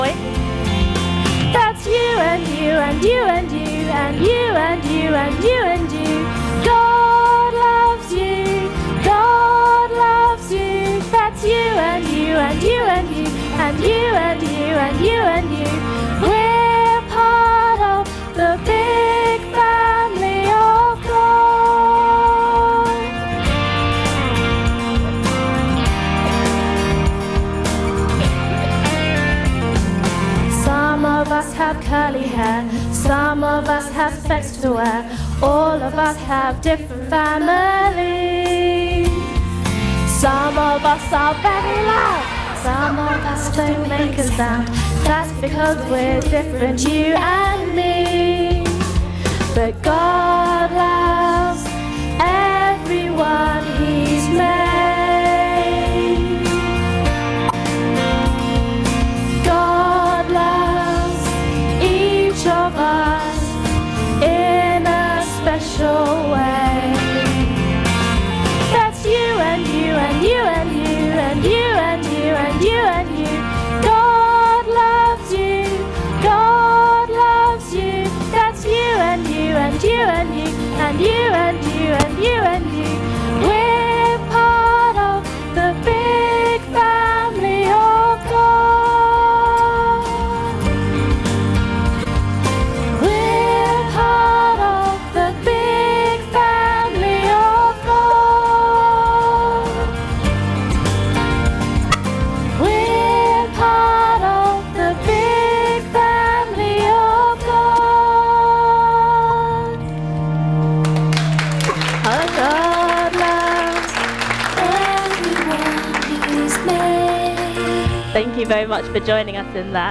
An ‘All age’ Youth Service.
Service Audio